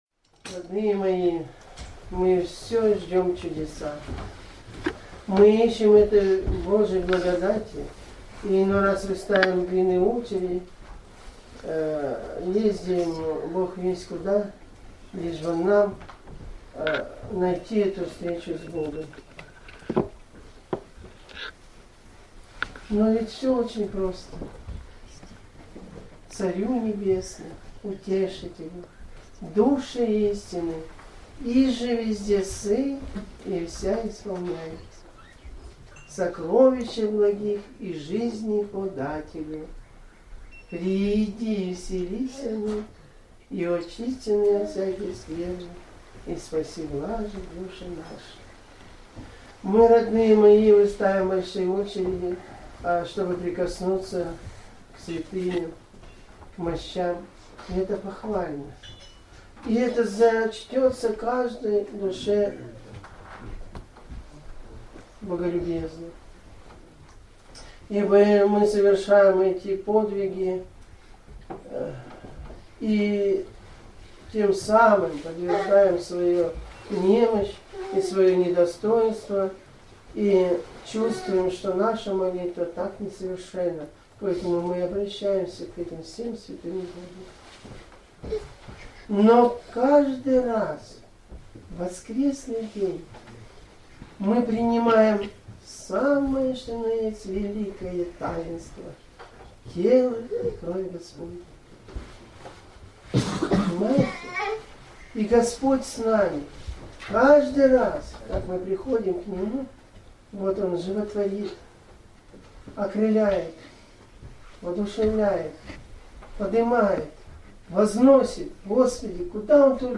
Проповеди настоятеля для прослушивания